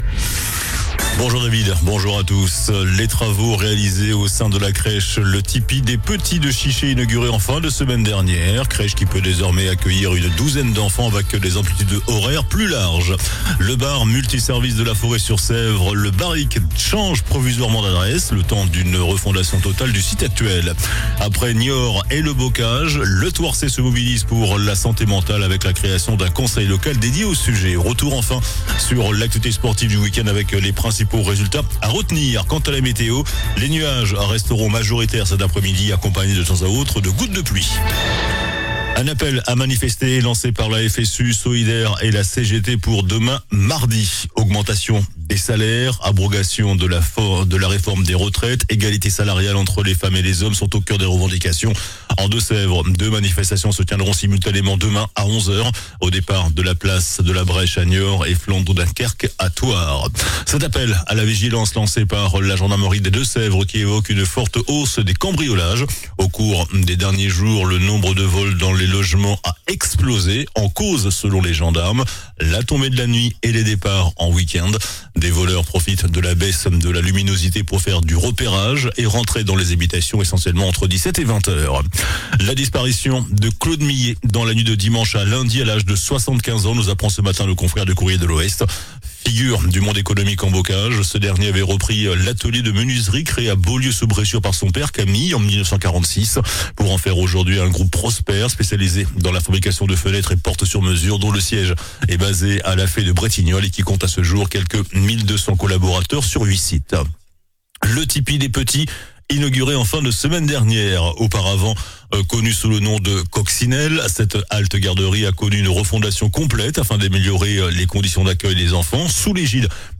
JOURNAL DU LUNDI 01 DECEMBRE ( MIDI )